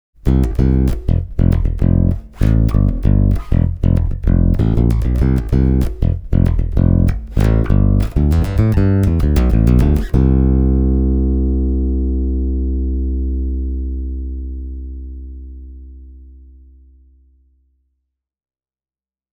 The basic amped-up sound of this bass is fantastic. Our review sample suffered from a mild volume reduction in the g-string’s output level, though.